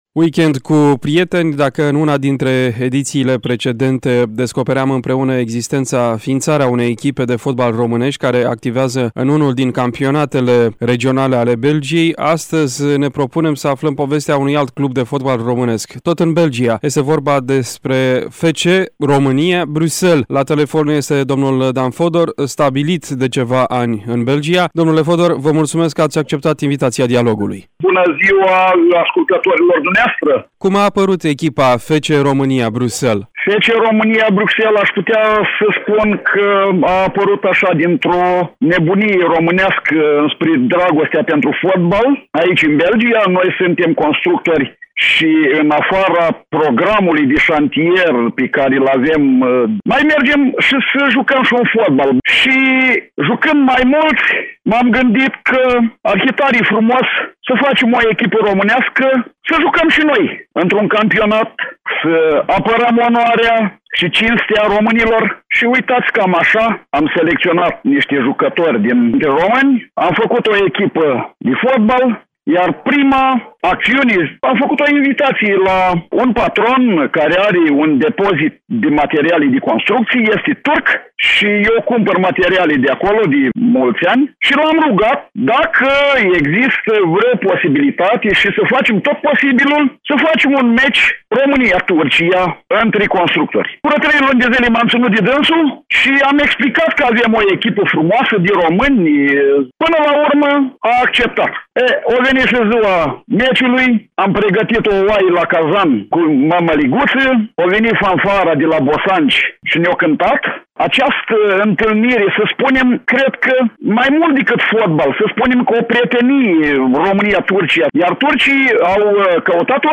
Un interviu